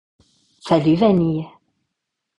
Bandes-son
Voix off
28 - 44 ans - Mezzo-soprano